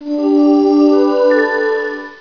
• one short musical jingle